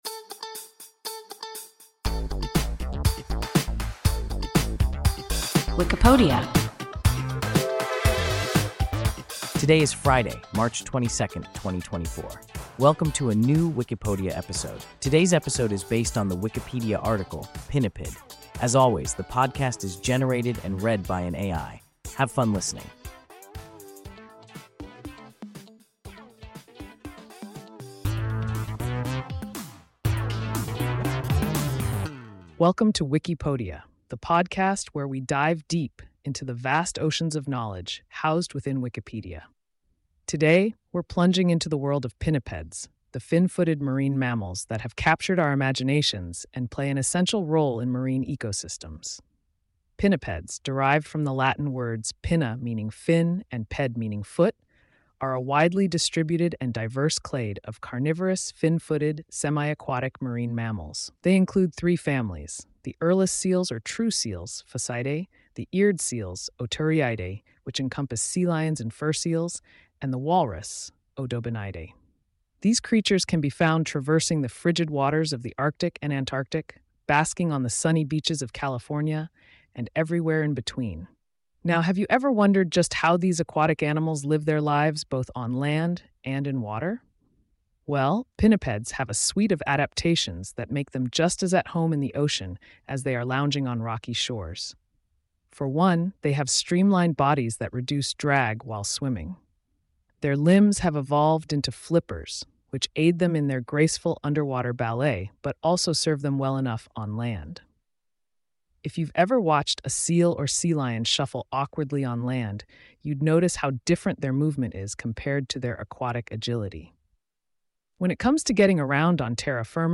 Pinniped – WIKIPODIA – ein KI Podcast